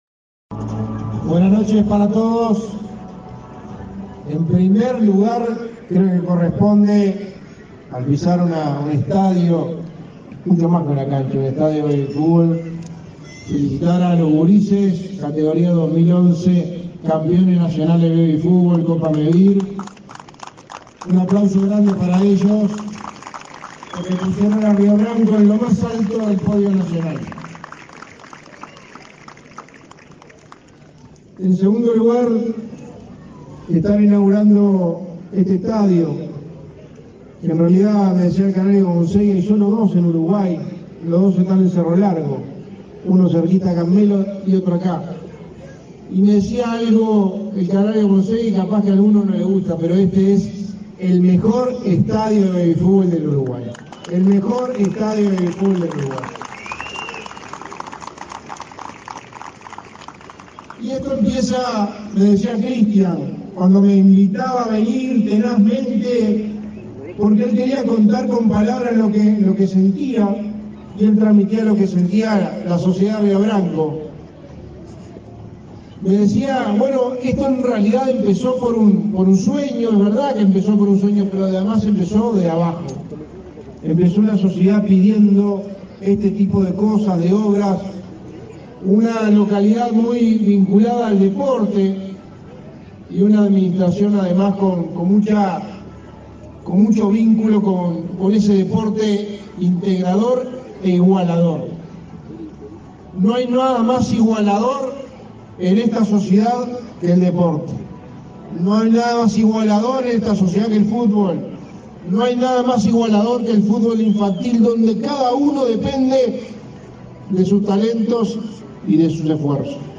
Palabras del secretario de Presidencia, Álvaro Delgado
En el marco de una recorrida por el departamento de Cerro Largo, el secretario de la Presidencia, Álvaro Delgado, participó, el 27 de abril, en la